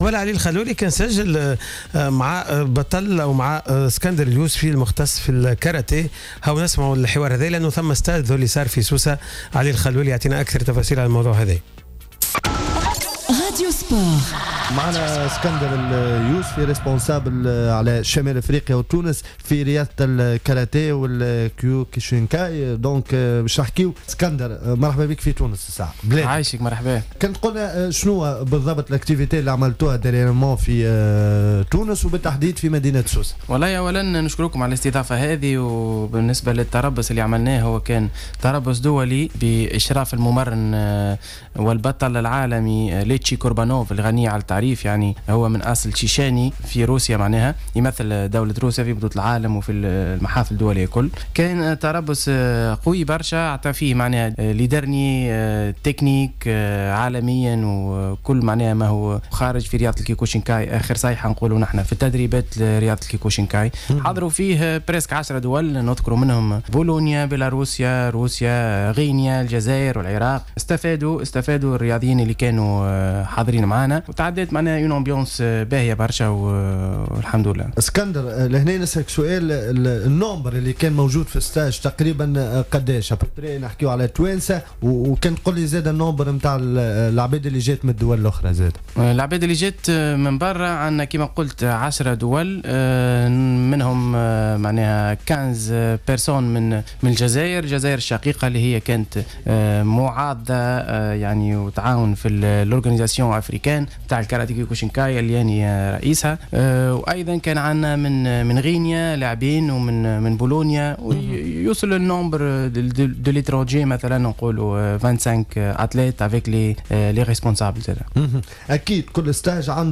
ضيف راديو سبور